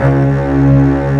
STR STRING01.wav